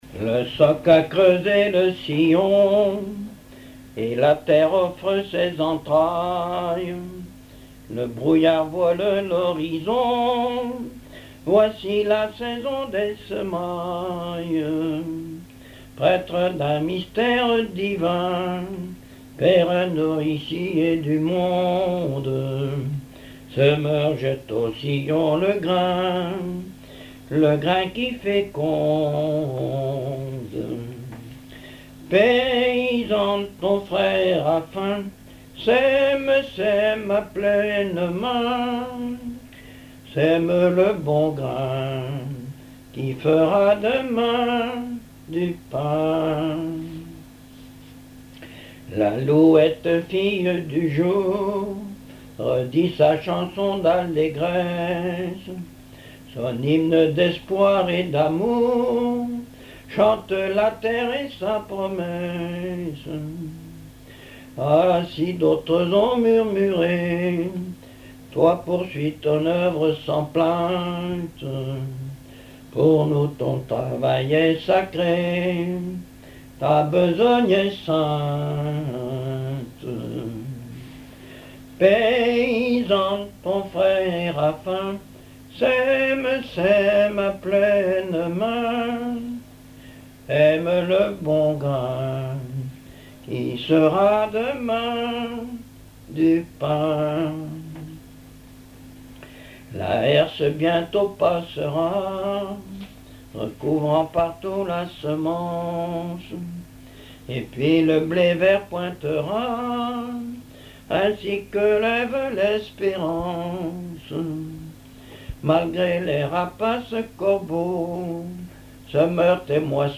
Genre strophique
contes, récits et chansons populaires
Pièce musicale inédite